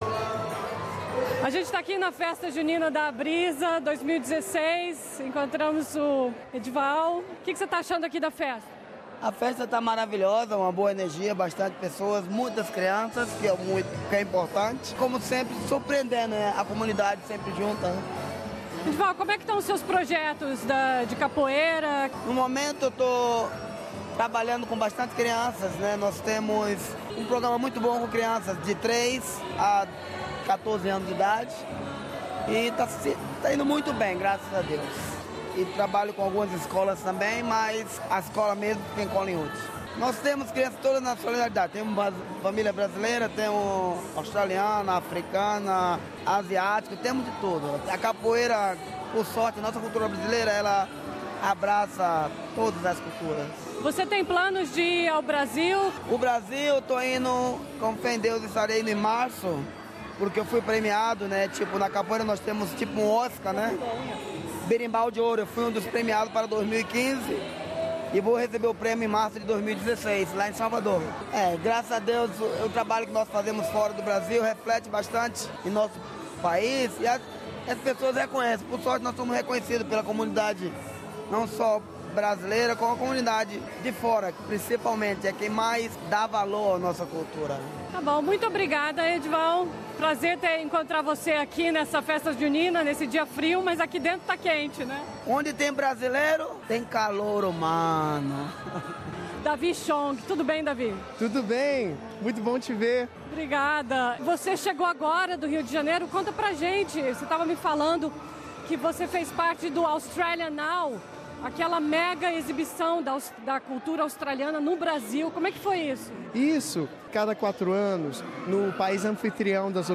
O maior evento do ano da ABRISA, a Festa Junina 2016, teve quadrilha, comidas típicas, banda e diversão para adultos e crianças. Ouça cobertura completa do evento que aconteceu na St Brigid's Hall.